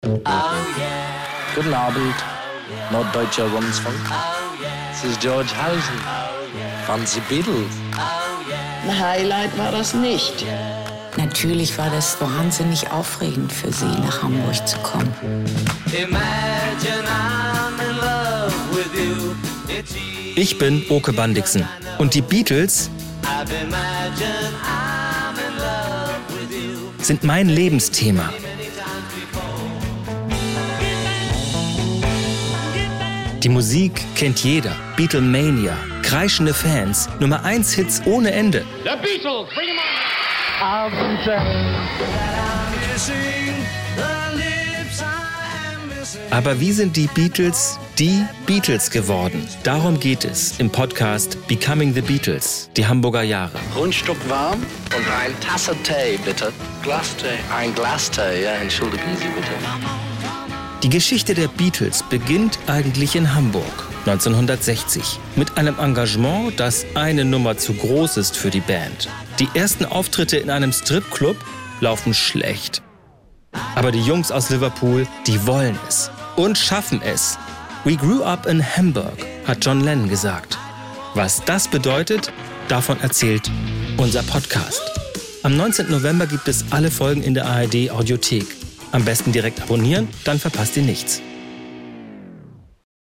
Geschichten vom Hamburger Kiez – mit viel Musik, O-Tönen von
Zeitzeugen und seltenem Archivmaterial.